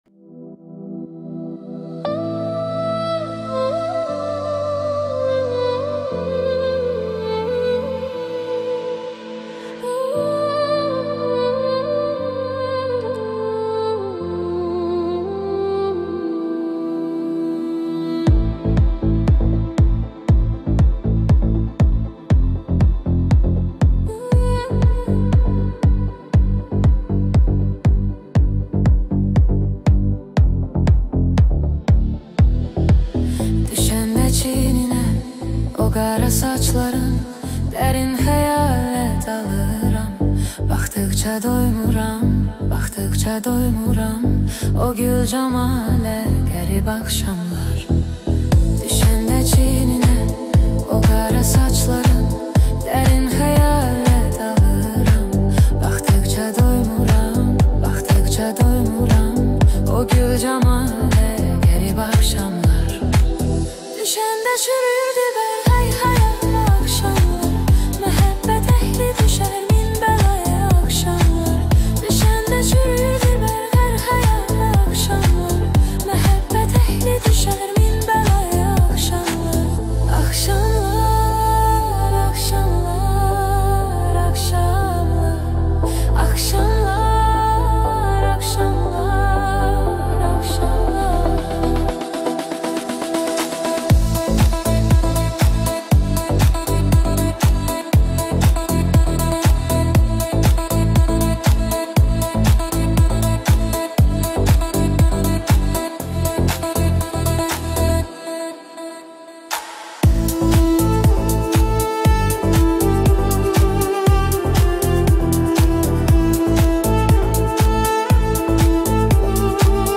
Azeri Remix Music Bass